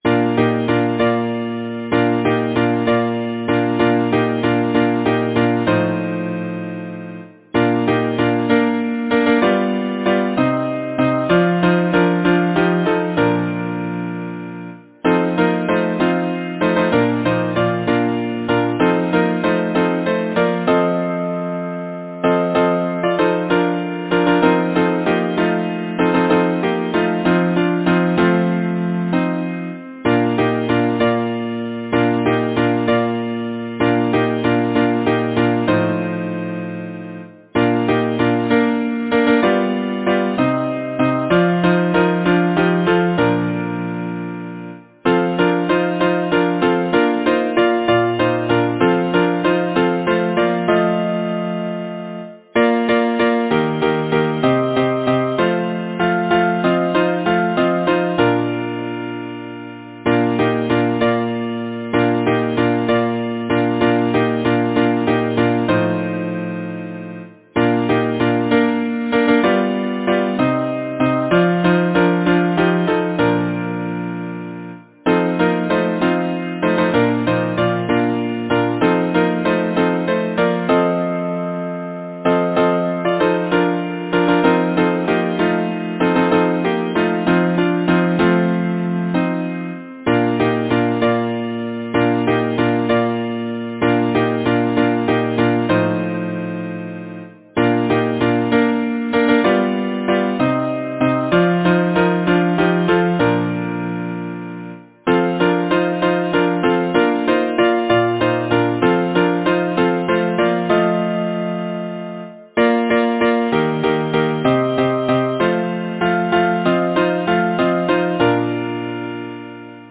Title: The fisher boy Composer: Howard Kingsbury Lyricist: Eliza Cook Number of voices: 4vv Voicing: SATB Genre: Secular, Partsong
Language: English Instruments: A cappella